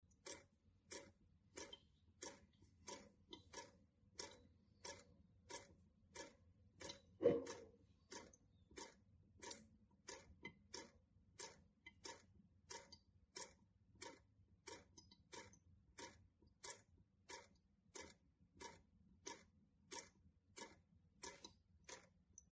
Звуки воды из крана
На этой странице собраны натуральные звуки воды из крана: от мягкого потока до отдельных капель.